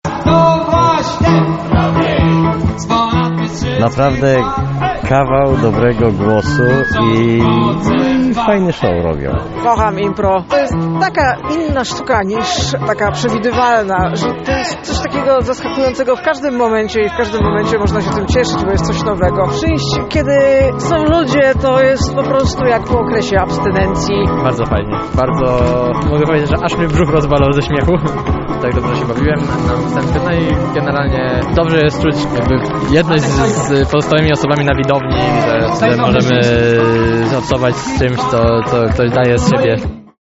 Uczestnicy z entuzjazmem rozmawiali o swoich przeżyciach.